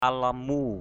/al-la-mu/ (Ar.) ilm علم (d.) sự học thức = savoir, connaissance. knowlege.